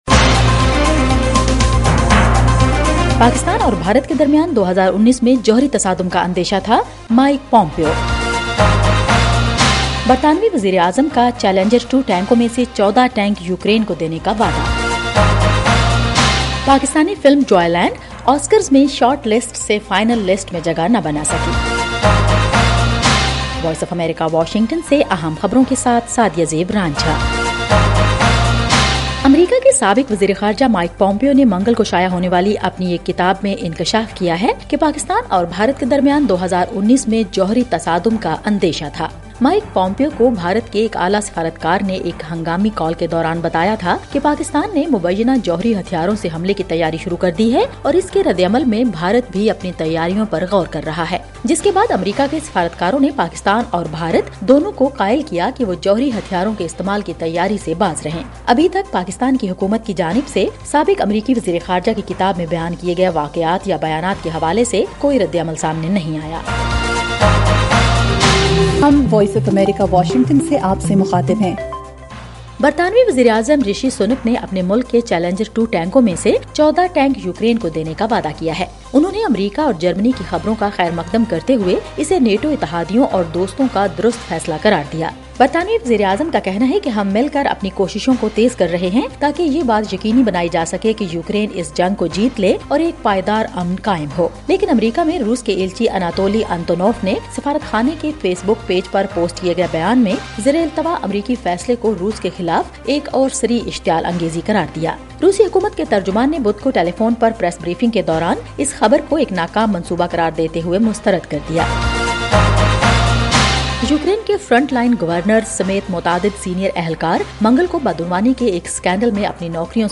ایف ایم ریڈیو نیوز بلیٹن : رات 10 بجے